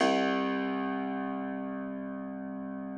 53l-pno02-C0.aif